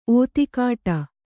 ♪ ōtikāṭa